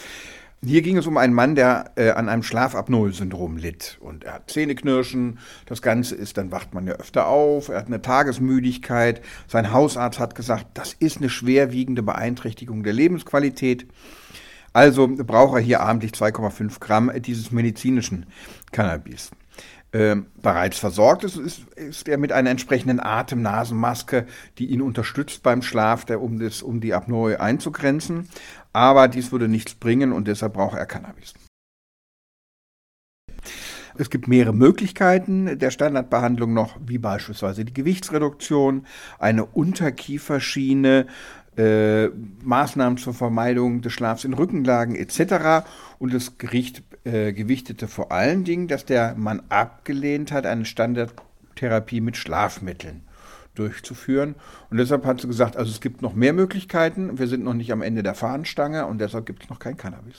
O-Ton + Kollegengespräch: Muss Krankenkasse Cannabis bei Schlafapnoesyndrom zahlen? – Vorabs Medienproduktion